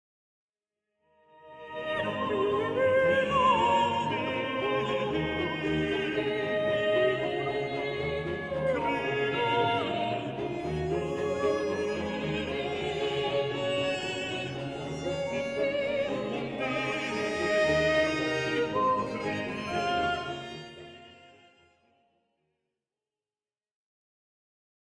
Version pour ensemble de solistes